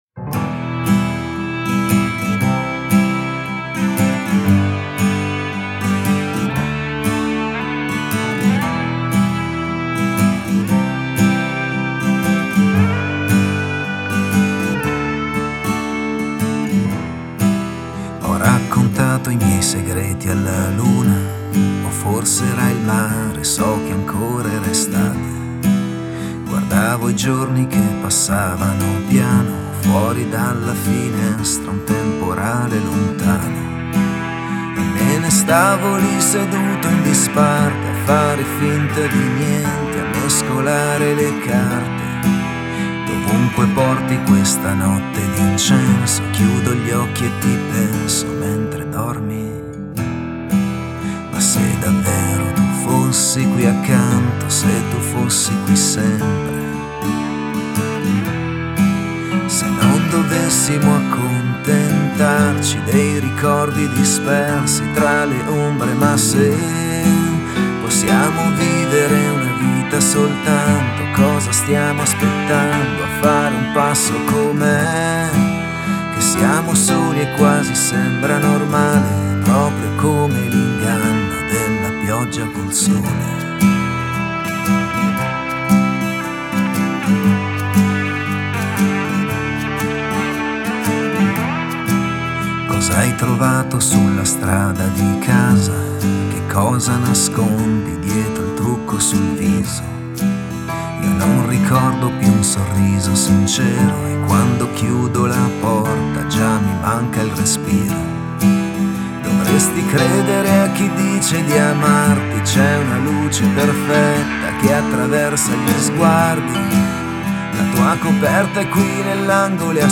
Genere: Cantautori.